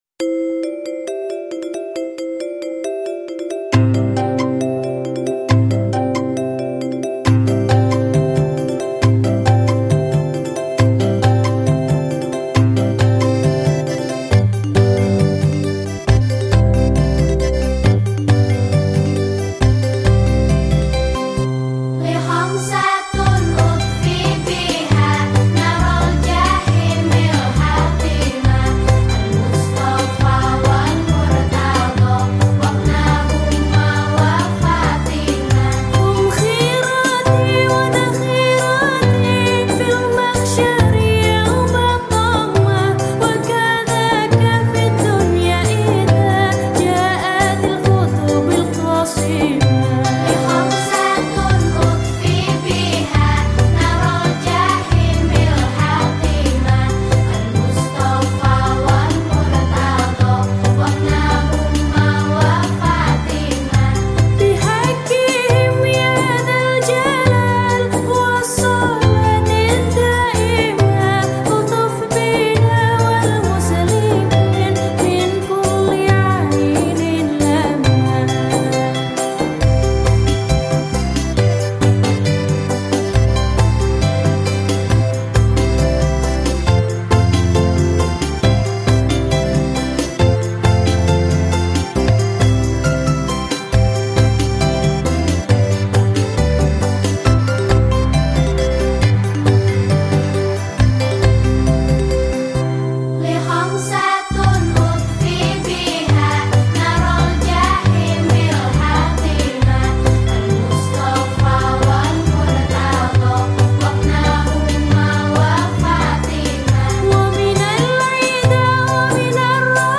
Genre Musik                              : Religi Sholawat